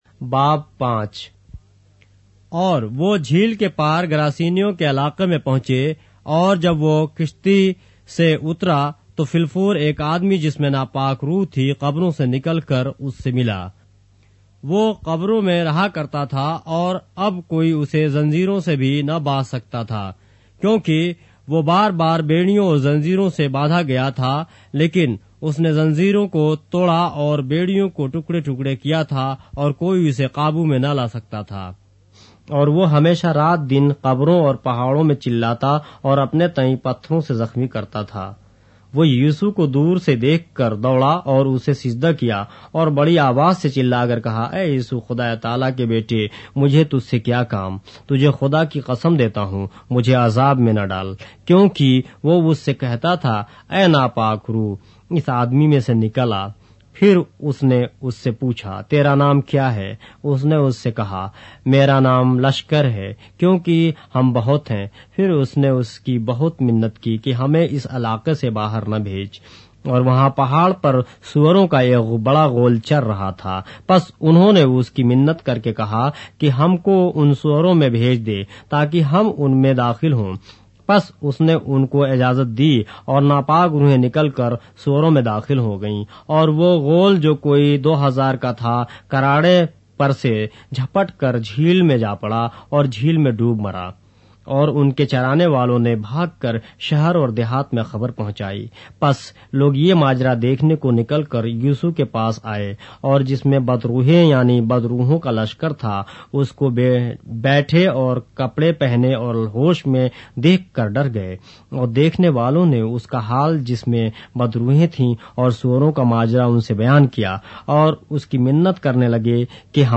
اردو بائبل کے باب - آڈیو روایت کے ساتھ - Mark, chapter 5 of the Holy Bible in Urdu